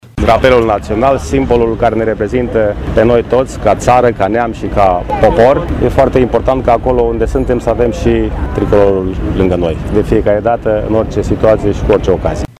Instituția Prefectului – Județul Brașov, în parteneriat cu Garnizoana Braşov, a organizat în Piaţa Tricolorului, o manifestare specială dedicată aniversării Zilei Drapelului Naţional al României.
La rândul său, primarul Brașovului, George Scripcaru, a spus: